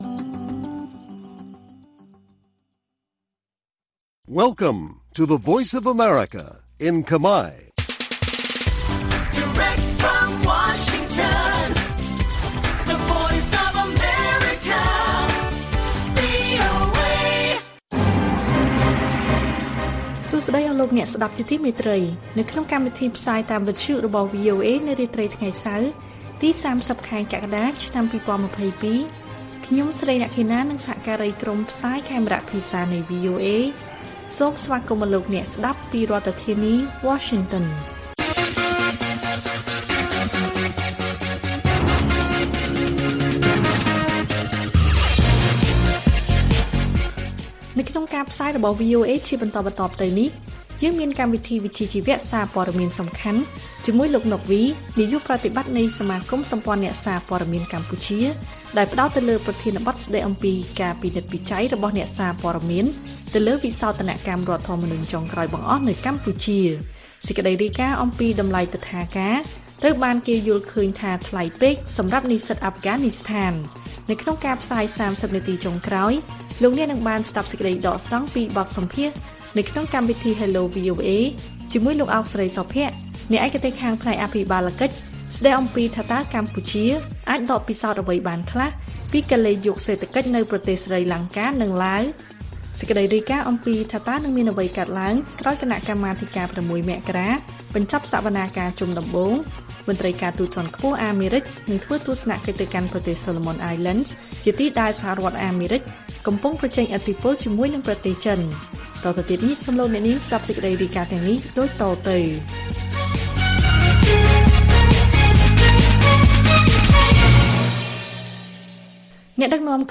ព័ត៌មានពេលរាត្រី ៣០ កក្កដា៖ បទសម្ភាសន៍អំពី«ការពិនិត្យរបស់អ្នកសារព័ត៌មានទៅលើវិសោធនកម្មរដ្ឋធម្មនុញ្ញចុងក្រោយបង្អស់នៅកម្ពុជា»